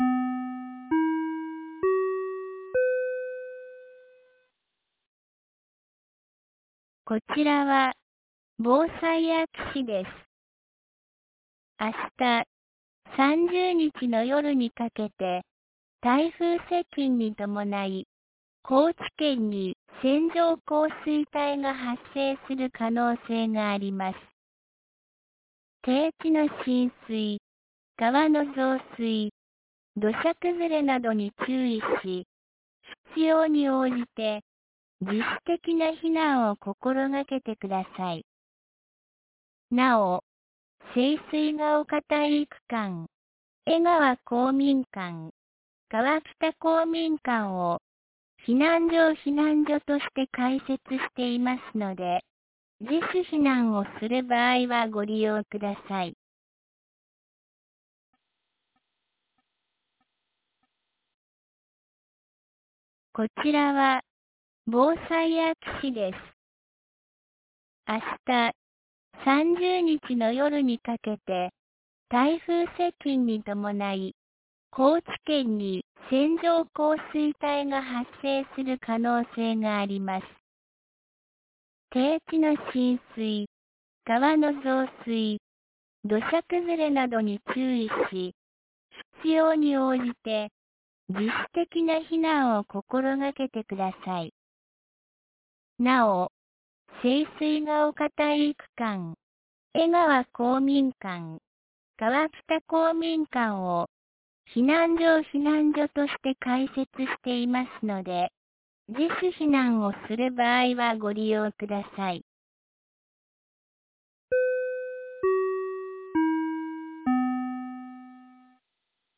2024年08月29日 15時27分に、安芸市より川北、江川へ放送がありました。